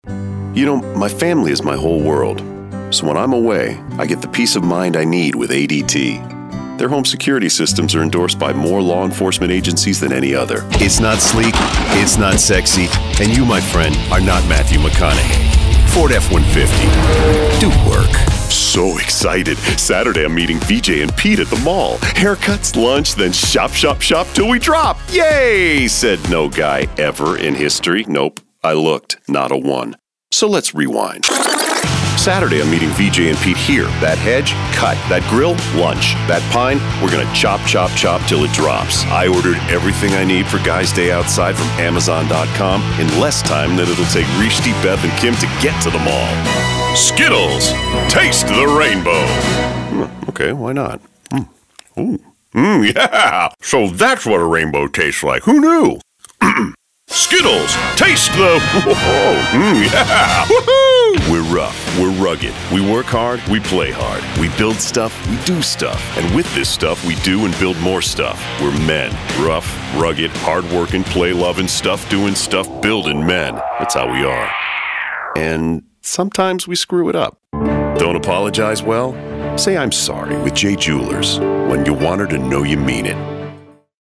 It’s a really good fit for my big, deep voice, and it feels very natural to bring the attitude and personality to the type of station I’m voicing for.
Commercial